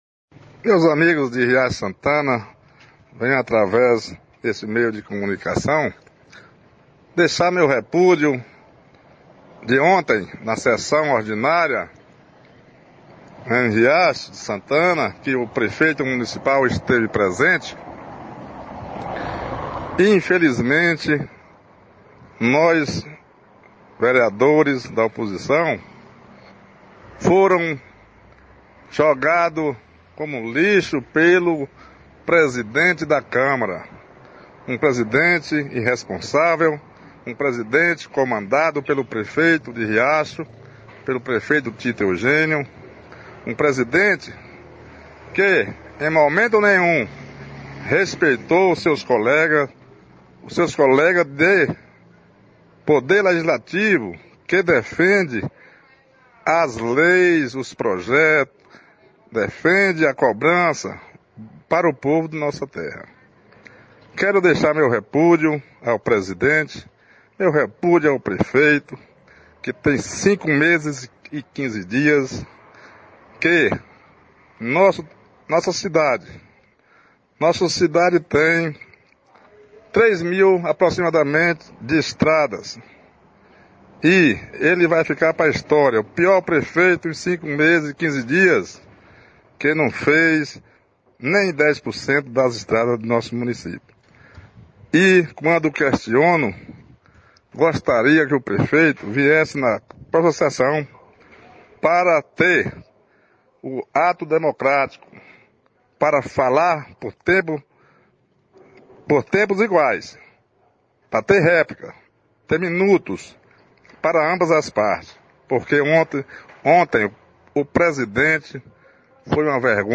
O vereador Uilson, um dos parlamentares que se sentiu ofendido pela postura do presidente, repudiou a atitude do comandante do legislativo riachense. “Nós vereadores da oposição fomos jogados como lixo pelo presidente da Câmara”, rebateu.
Vereador-Uilson-fala-sobre-o-fato-online-audio-converter.com_.mp3